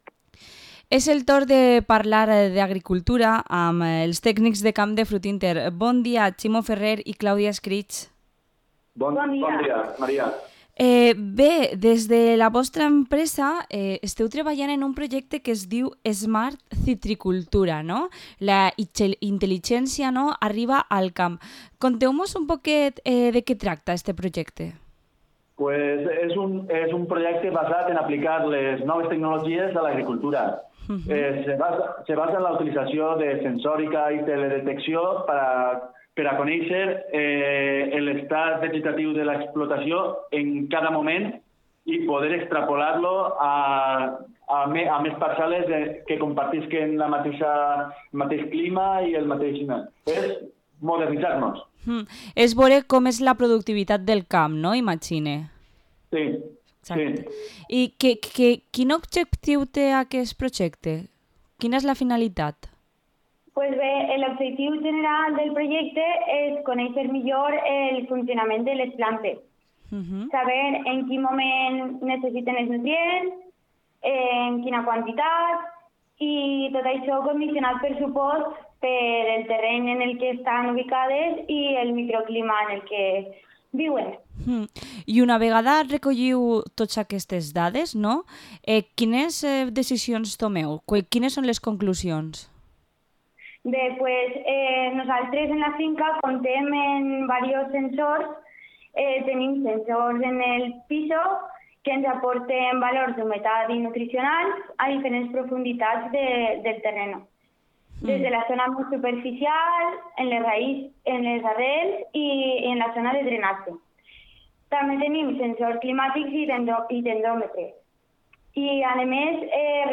Entrevista a los técnicos de campos de Frutinter